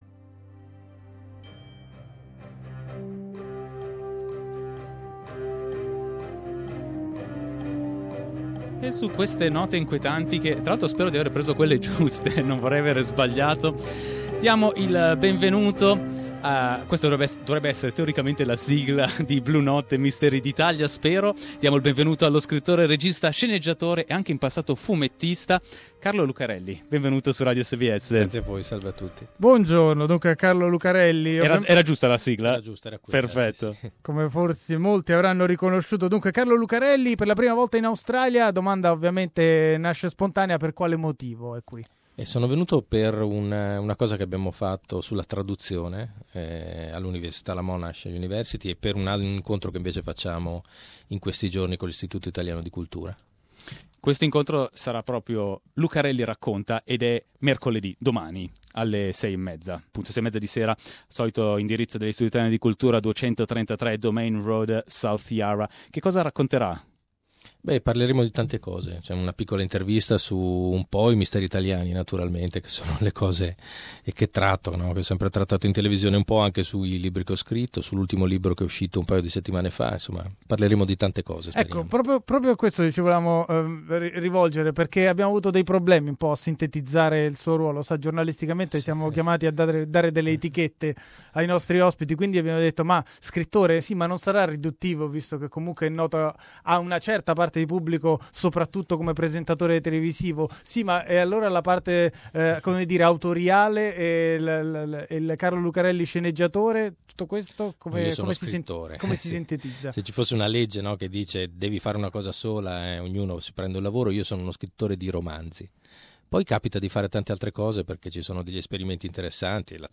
"Almost Blue" author Carlo Lucarelli talks about his writing and his Melbourne visit in a live interview.